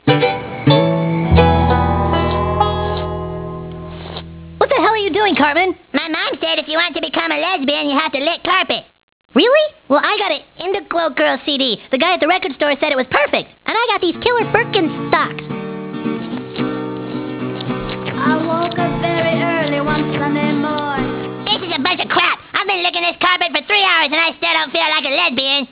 LESBIAN Stan,cartman,kyle,kenny trying to turn into lesbians.......